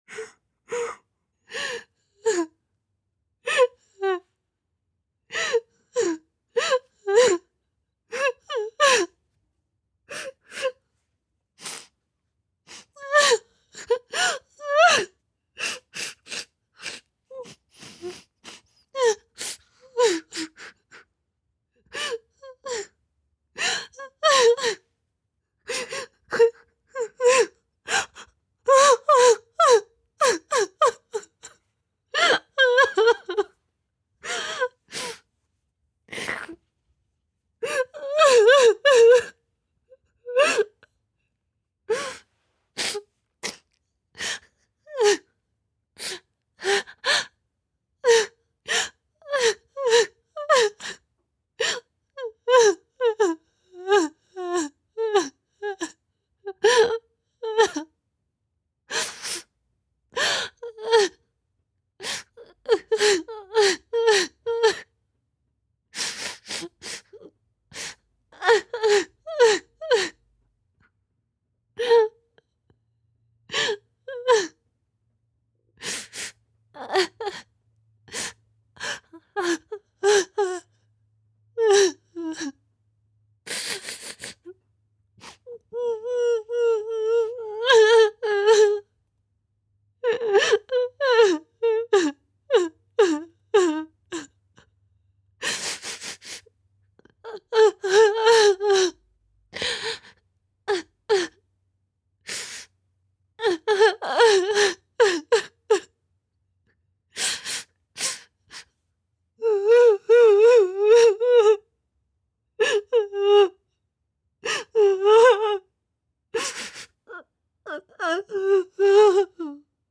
SFX声乐 — — 哭女(Vocal Cry Female)音效下载
SFX音效